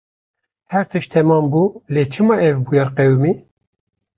Lees verder Betekenis (Engels) event Vertalingen events affair event Vorgang Geschehen evento Episode olay Ereignis Fall Frequentie B1 Uitgesproken als (IPA) /buːˈjɛɾ/ Etymologie (Engels) From bû (“to be”) + -y- + -er.